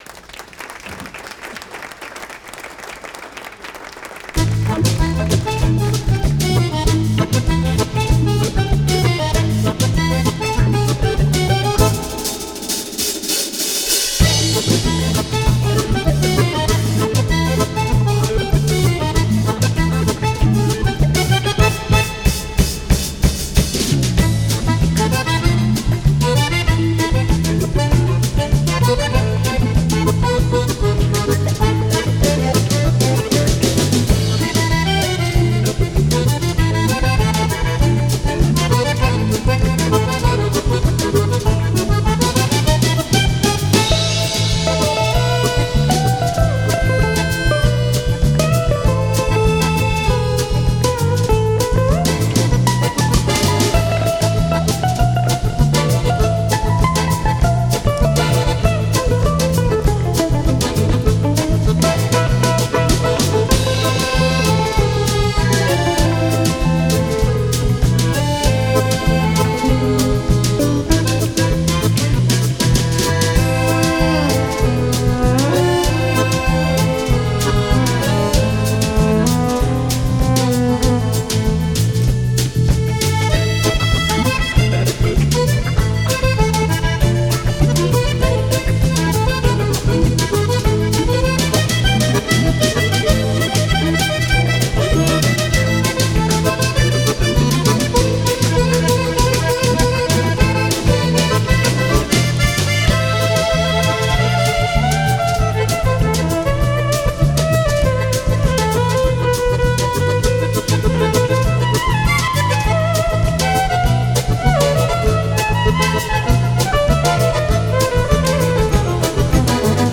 скрипка, вокал.
бас, вокал.
аккордеон, ф-но, вокал.
ударные.